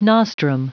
Prononciation du mot nostrum en anglais (fichier audio)
Prononciation du mot : nostrum